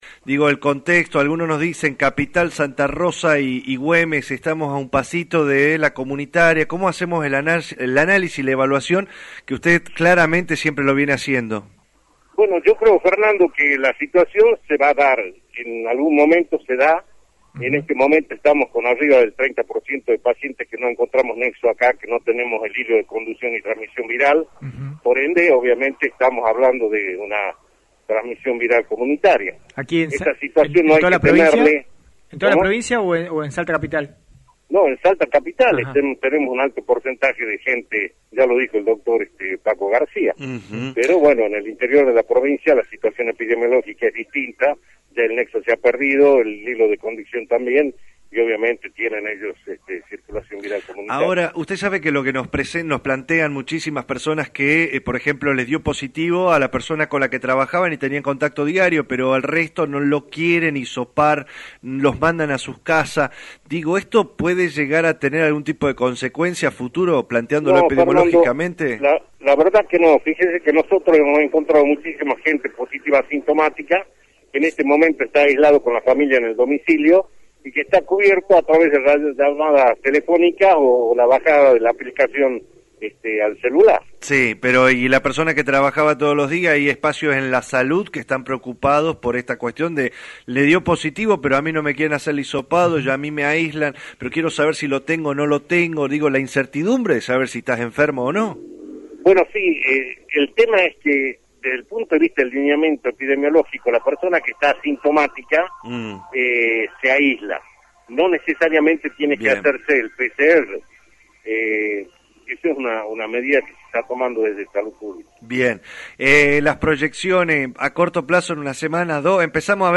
Lo destacado de la entrevista fue el momento de la consulta de circulación viral.